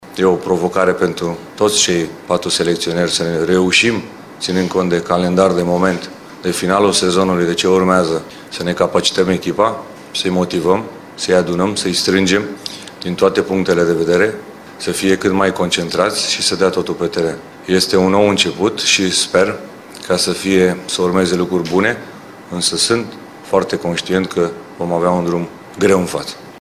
Încercăm mâine să fim cât mai exacţi, pragmatici şi eficienţi”, a afirmat tehnicianul în conferinţa susţinută la Podgorica.
Iordănescu a vorbit despre contextul în care încep jocurile din Liga Națiunilor: